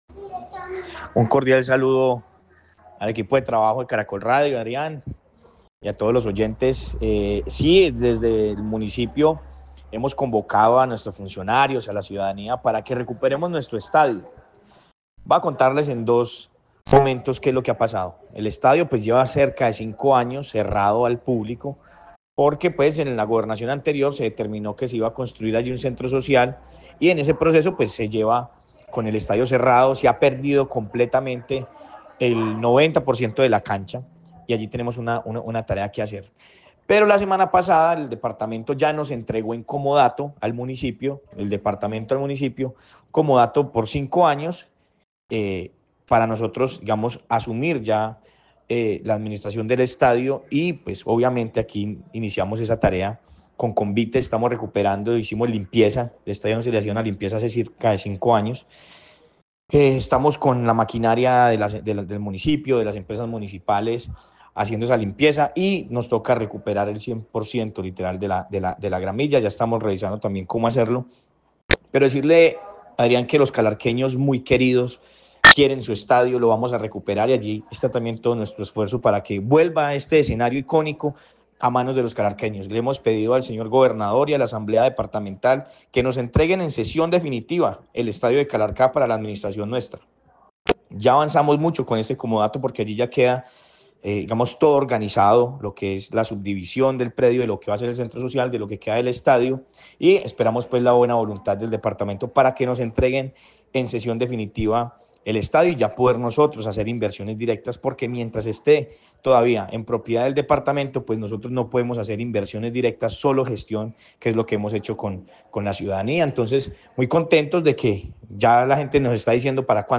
Sebastián Ramos, alcalde de Calarcá